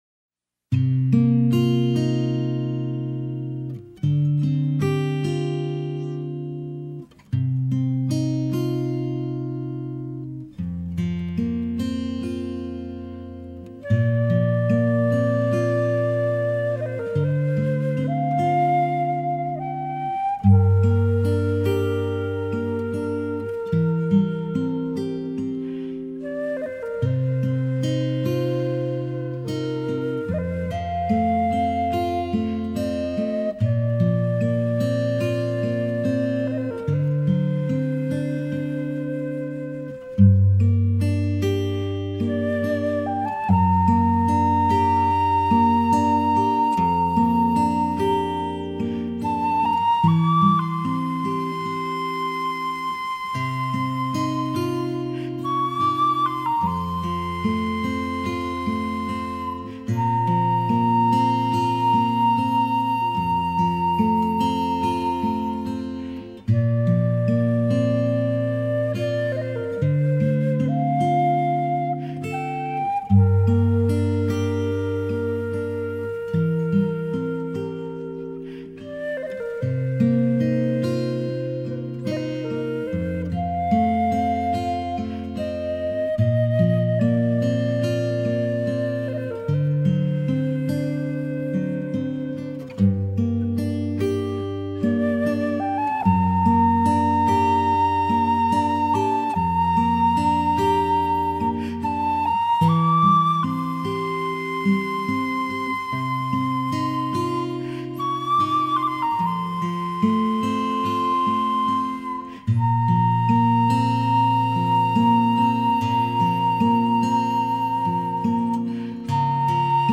instrumental music
Soundtrack Music